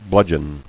[bludg·eon]